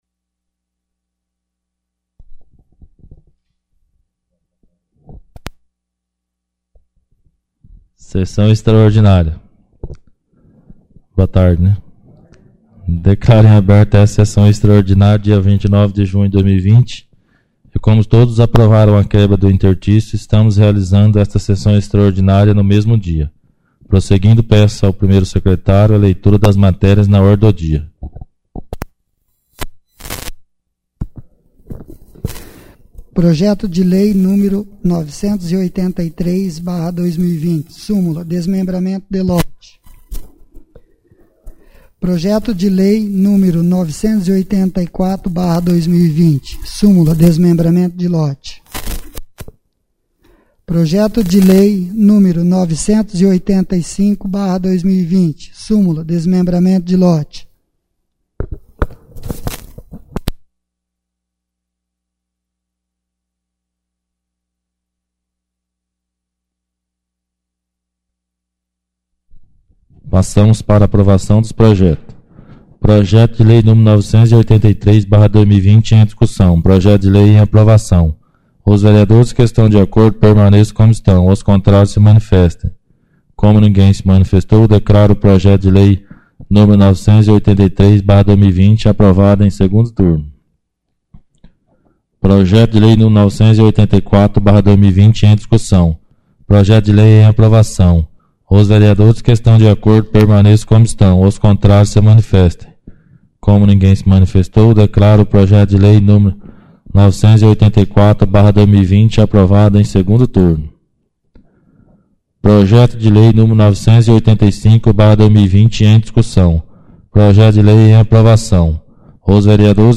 9ª Extraordinária da 4ª Sessão Legislativa da 11ª Legislatura